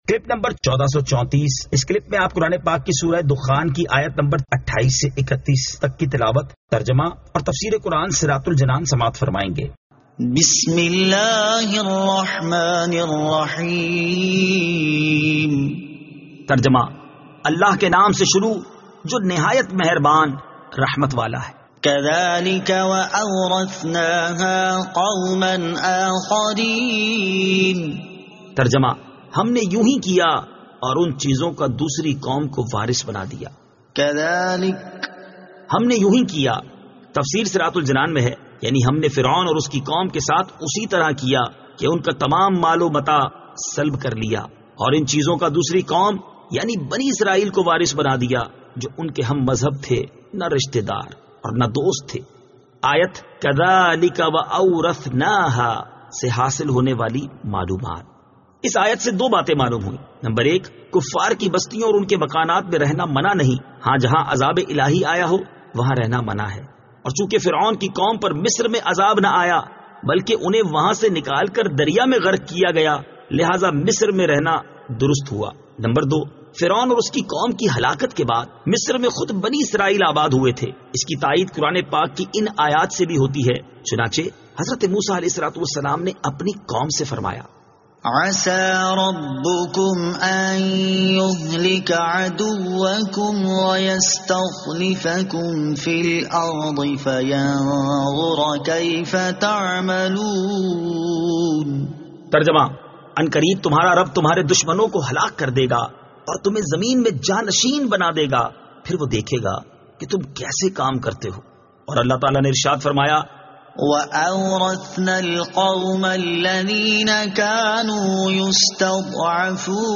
Surah Ad-Dukhan 28 To 31 Tilawat , Tarjama , Tafseer